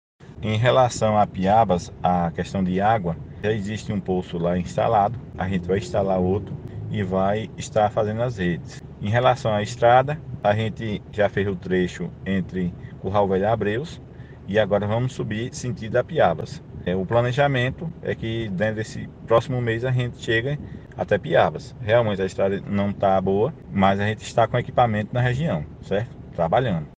Secretário André Custódo: resposta ao Ouvinte Oline